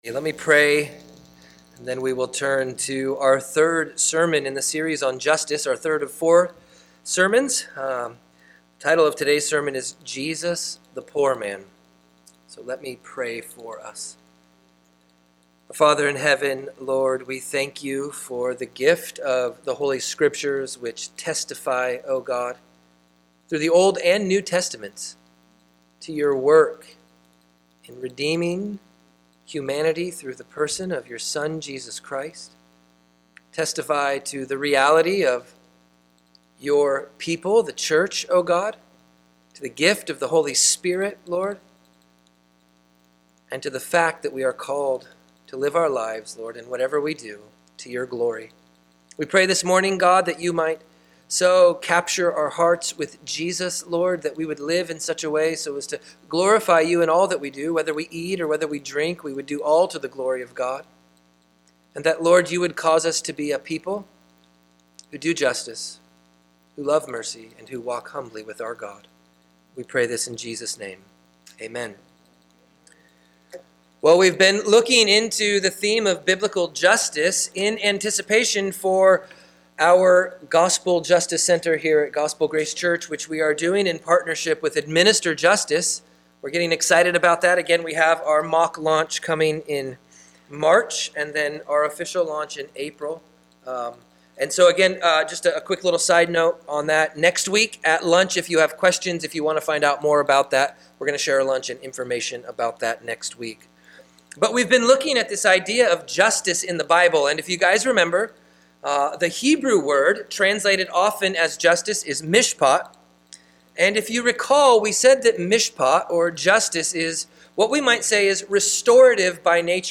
Feb 25, 2022 Jesus, The Poor Man MP3 SUBSCRIBE on iTunes(Podcast) Notes Discussion Sermons in this Series This is the third sermon in our series on biblical justice. In this sermon we look into God's unique identification with the poor, especially as it culminates in the incarnation of Jesus Christ.